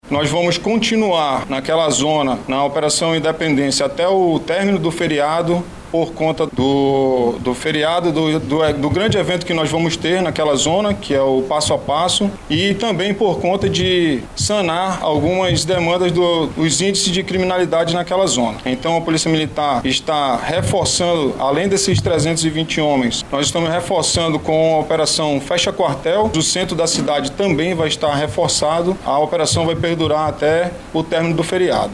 Segundo o comandante da Polícia Militar do Amazonas, Klinger Almeida, mais de 320 irão atuar no combate a criminalidade na região.